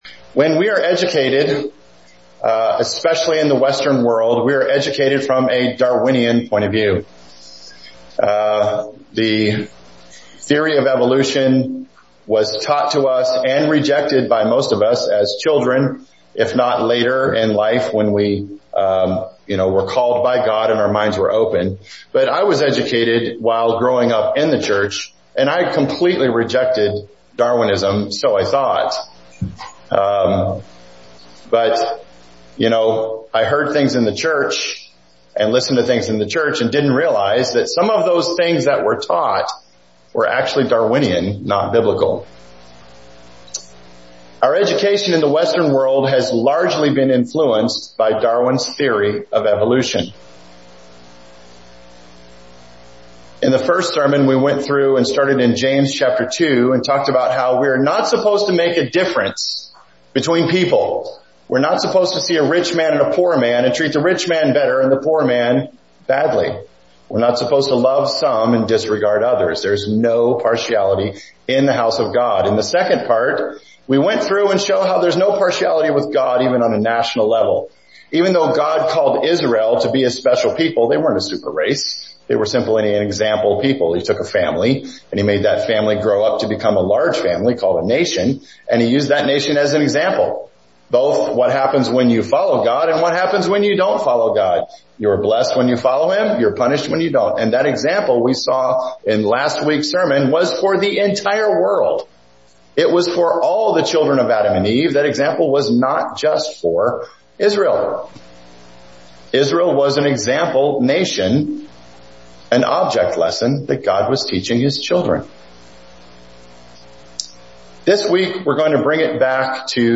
In the first sermon, we went through and started in James chapter 2 and talked about how we are not supposed to make a difference.